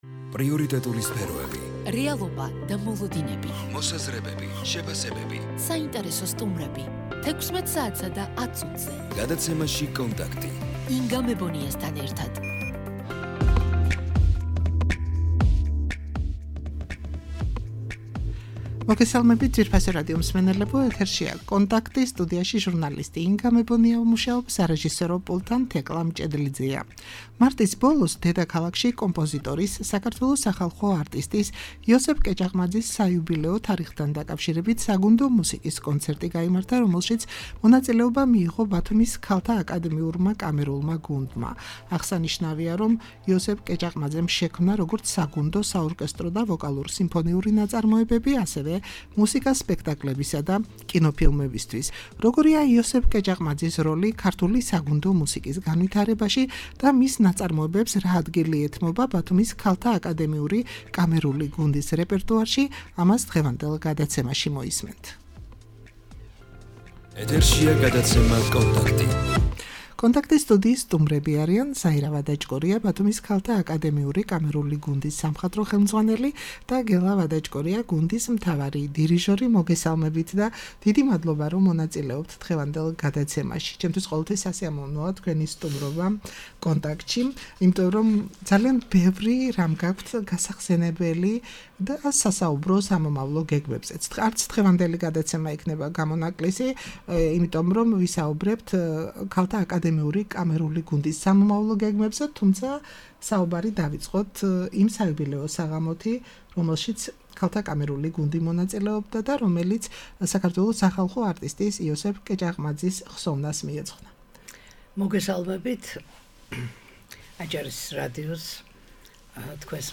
# ბათუმის ქალთა აკადემიური კამერული გუნდი იოსებ კეჭაყმაძის საიუბილეო საღამოზე